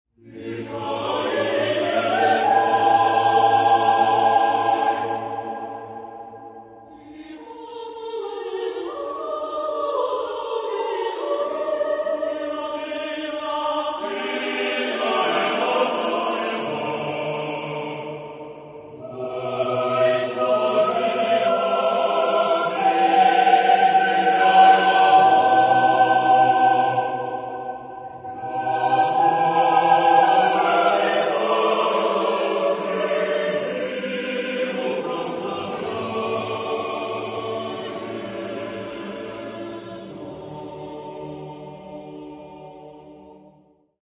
Каталог -> Классическая -> Хоровое искусство